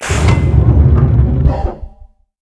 ol11_blastdoor_close.wav